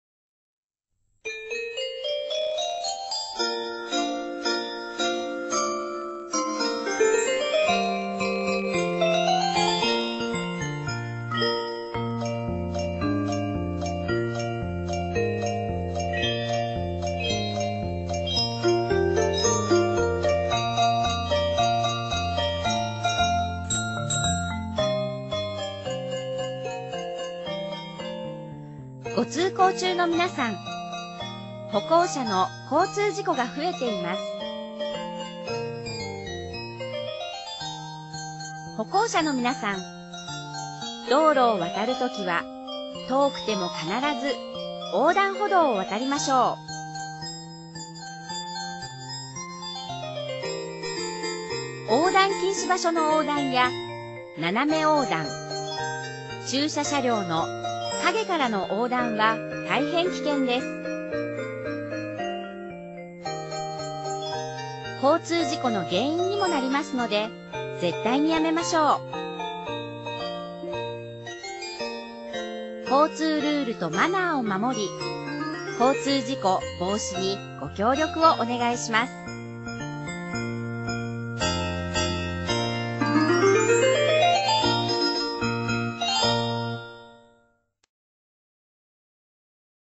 内容は、「ピーポくんのうた」オルゴールバージョンをBGMに、歩行者・自転車など、テーマ別に大切なルールやマナーを呼びかけています。